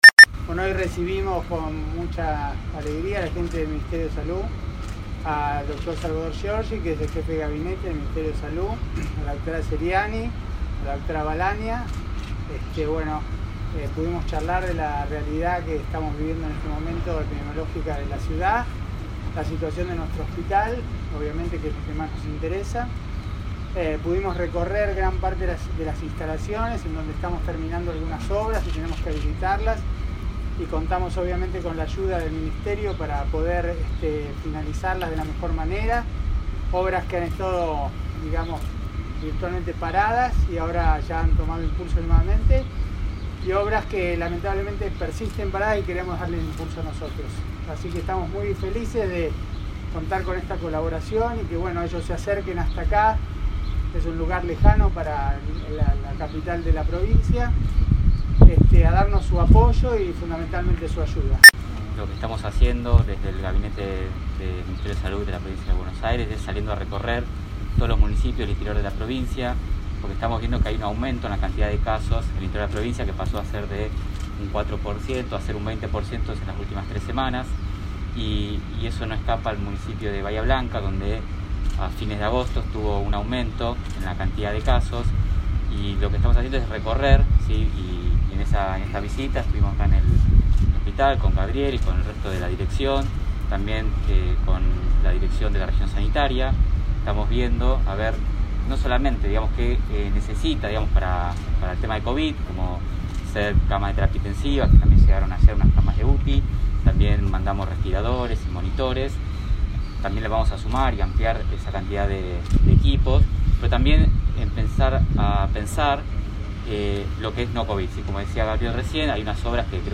Conferencia de prensa de representantes del Ministerio de Salud de la Provincia junto con el Dr. Gabriel Peluffo – URBANA 93.9
En medio de la inquietud que genera la situación epidemiológica local, visitaron la ciudad representantes del Ministerio de Salud de la Provincia. Salvador Giorgi, jefe de Gabinete del Ministerio de Salud provincial y Leticia Ceriani, Subsecretaria de Gestión de la Información, Educación permanente y Fiscalización brindaron una conferencia de prensa luego de una recorrida por el Hospital Penna junto con el Dr. Gabriel Peluffo, director del centro de salud interzonal.